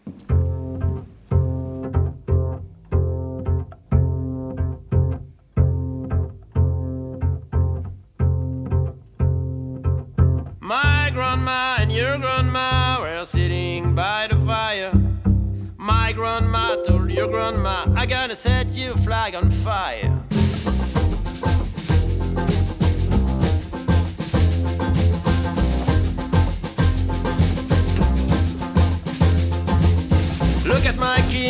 trompette, bugle
saxophone ténor et soprano
contrebasse
banjo, guitare ténor
batterie, percussions